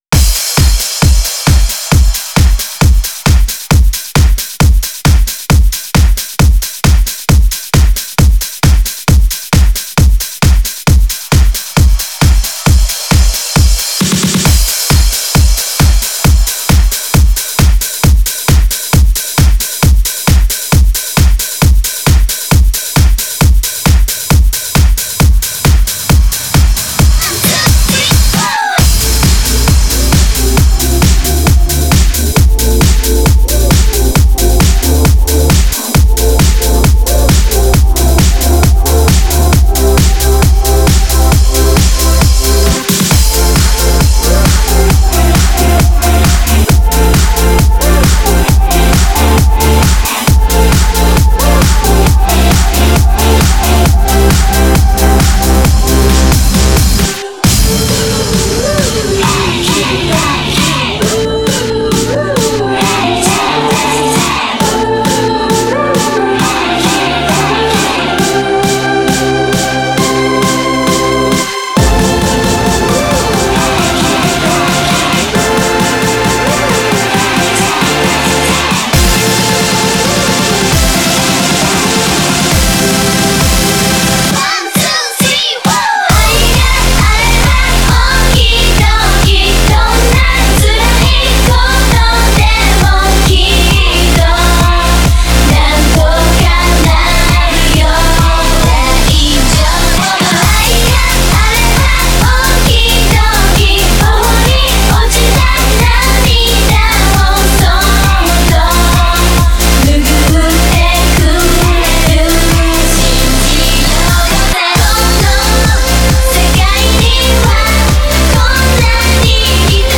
Genre(s): House